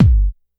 Kick 1_01.wav